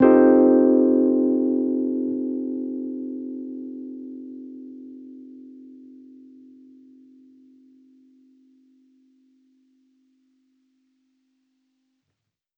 Index of /musicradar/jazz-keys-samples/Chord Hits/Electric Piano 1
JK_ElPiano1_Chord-Cm13.wav